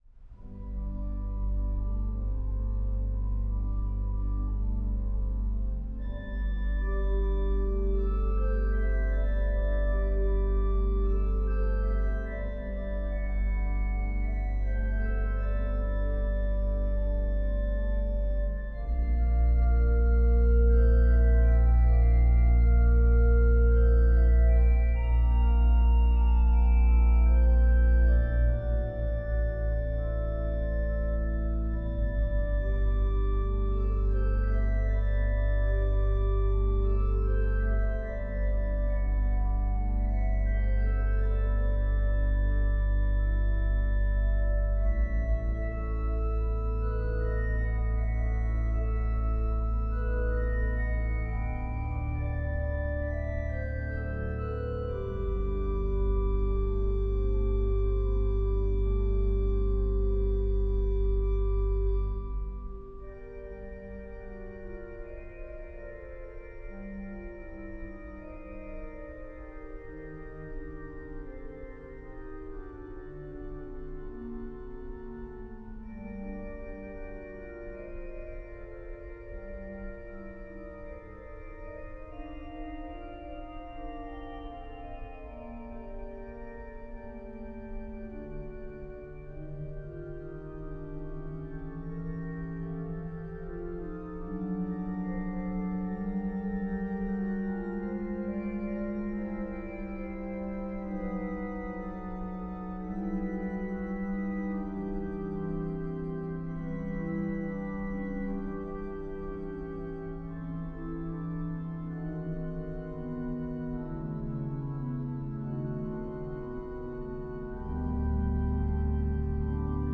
Voicing: Org 2-staff